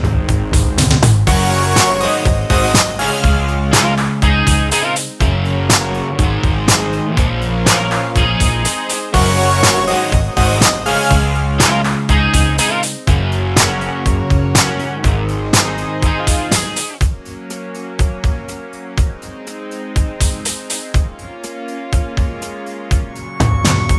Minus Solo Guitar Pop (1980s) 4:35 Buy £1.50